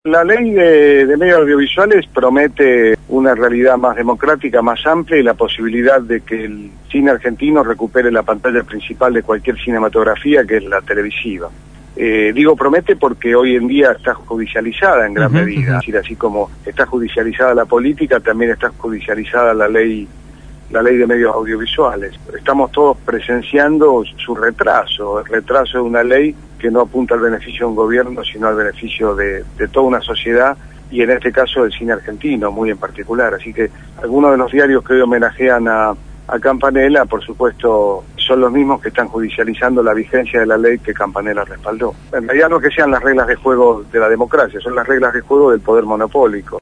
Jorge Coscia, Secretario de Cultura de la Nación, fue entrevistado